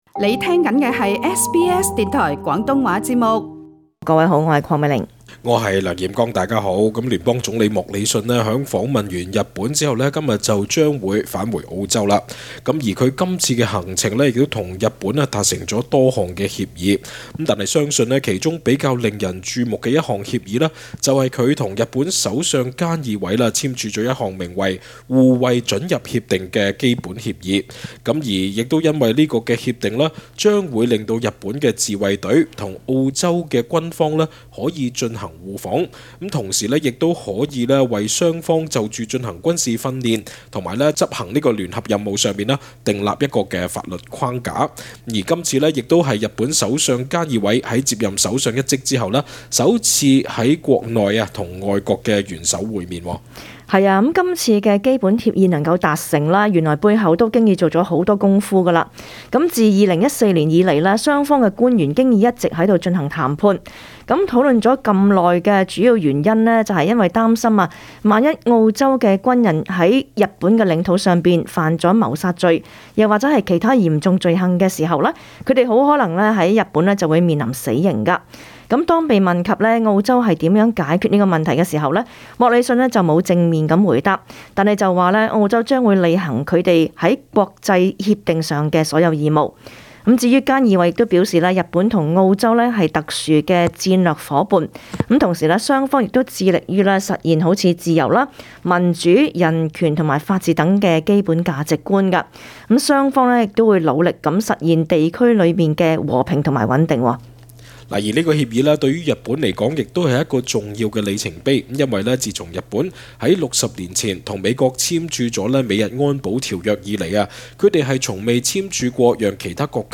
Source: AAP SBS广东话播客 View Podcast Series Follow and Subscribe Apple Podcasts YouTube Spotify Download (8.92MB) Download the SBS Audio app Available on iOS and Android 总理莫里逊（Scott Morrison）在访问完日本之后，今日将会返回澳洲。